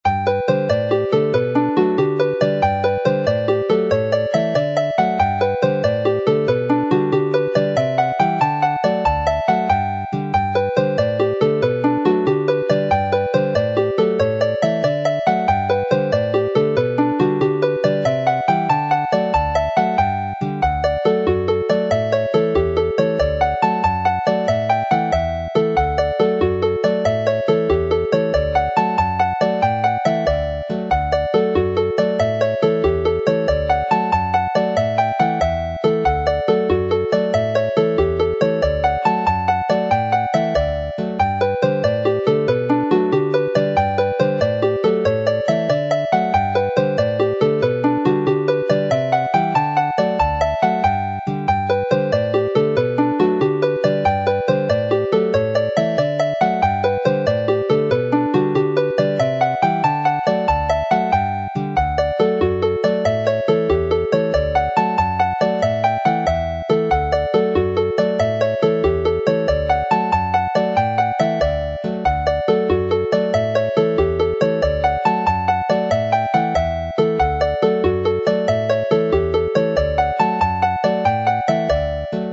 This lively set of jigs